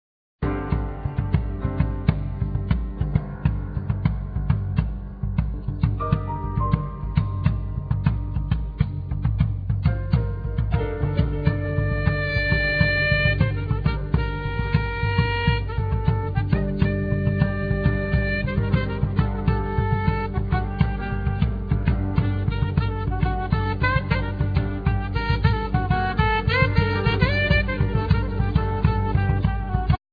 Saxophone
Keyboards
Guitars
Bass
Percussions
Drums
Vocals